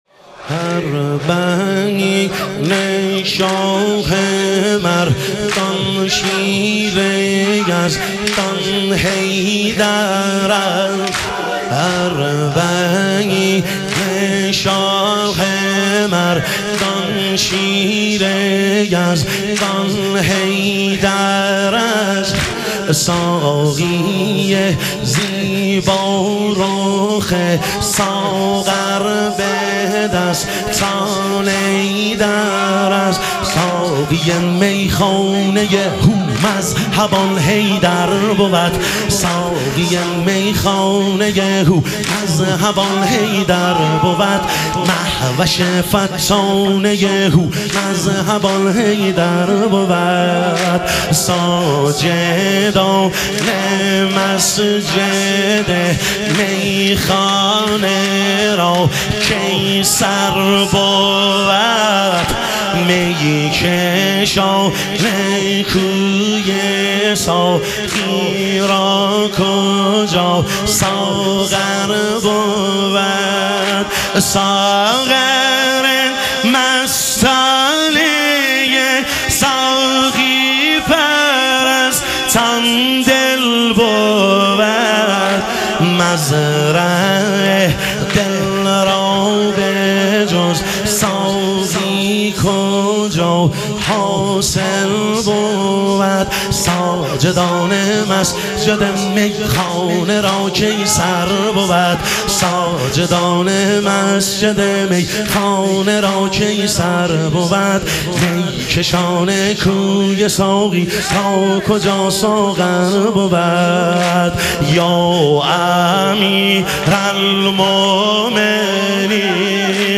اربعین امیرالمومنین علیه السلام - واحد